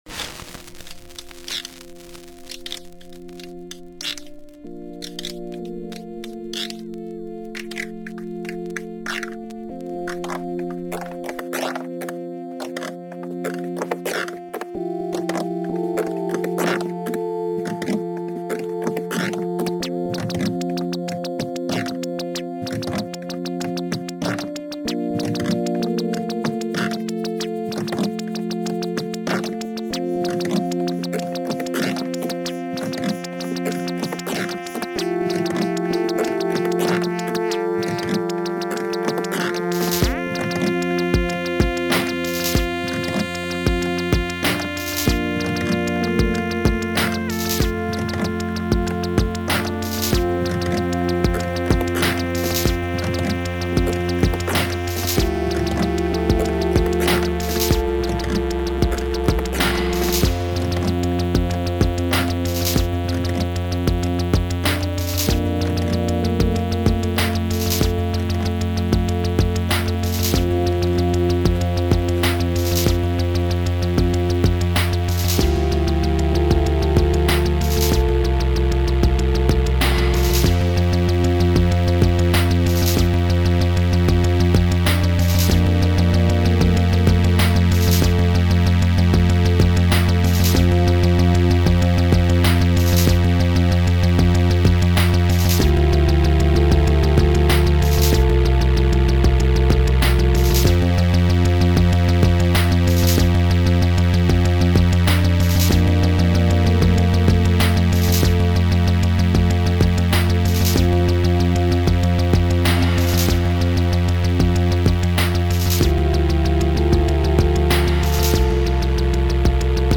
Genre: Sex Music.